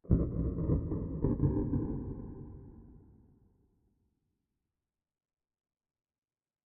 Minecraft Version Minecraft Version latest Latest Release | Latest Snapshot latest / assets / minecraft / sounds / ambient / nether / nether_wastes / addition7.ogg Compare With Compare With Latest Release | Latest Snapshot